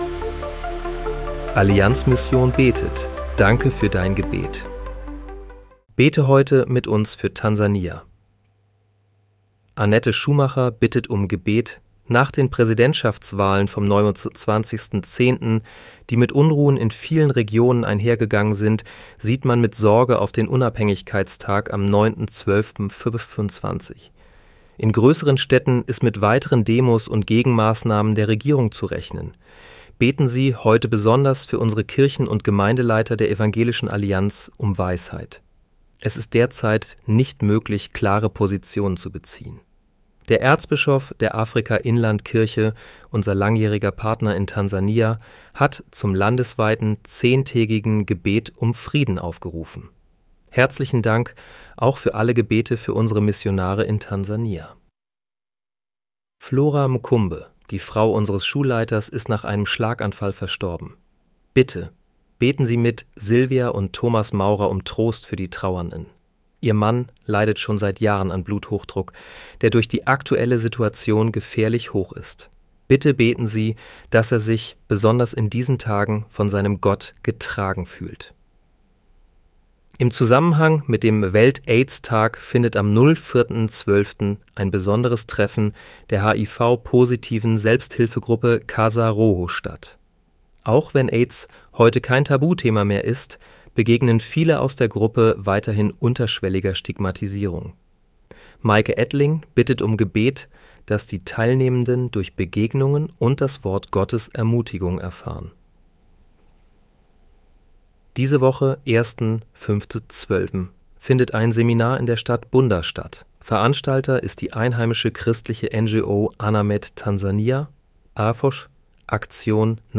Bete am 04. Dezember 2025 mit uns für Tansania. (KI-generiert mit